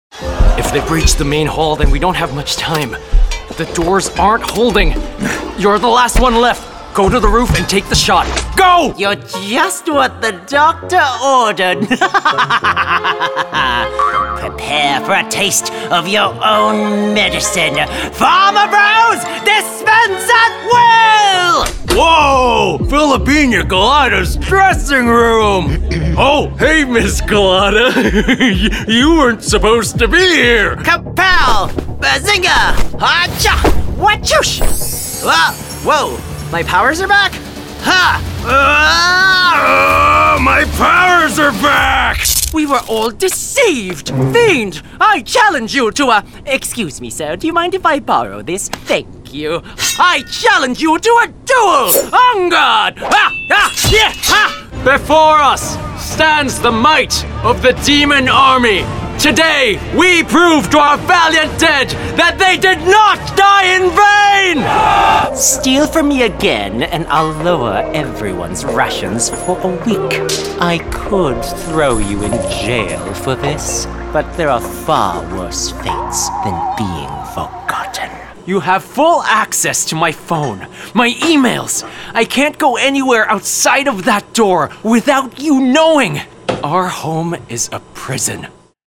Animation Demo
animation-demo.mp3